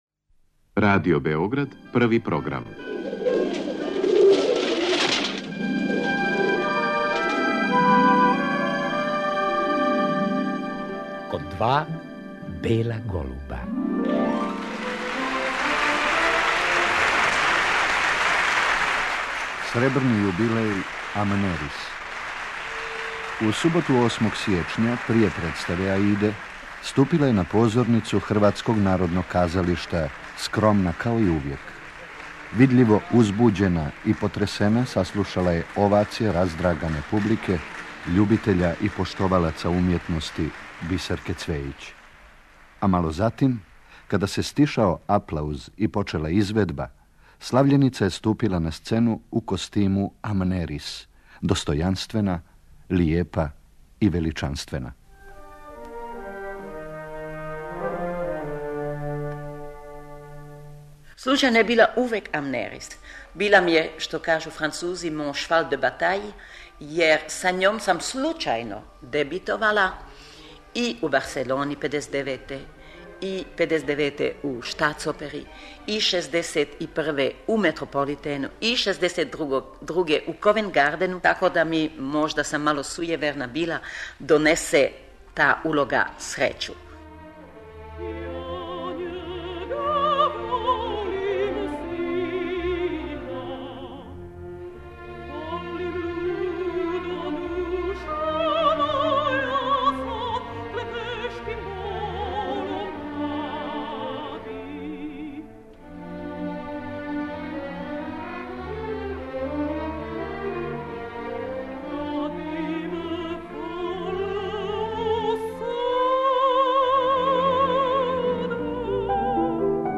Критичари је описују као вердијевски мецесопран, суверен певач, младалачког свежег гласа.